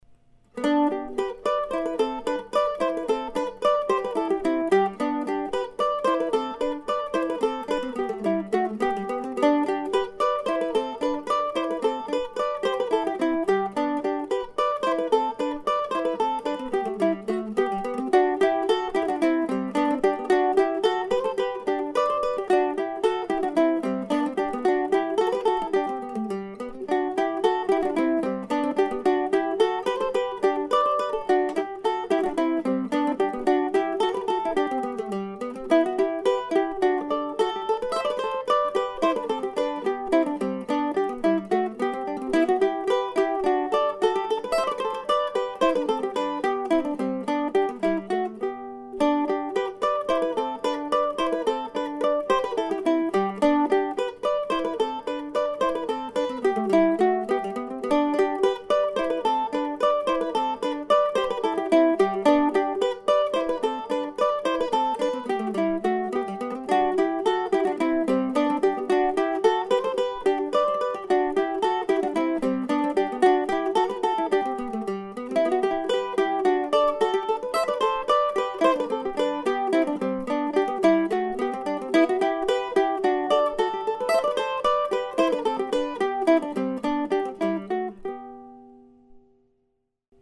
I've been working on a new book, tentatively titled Mandolin Duos: 28 easy to intermediate pieces for two mandolins, with a goal of having it ready in time for the Classical Mandolin Society of America's annual convention that is being held Oct.13-17 in Seattle.
As presented here the music is AA,BB,CC in form.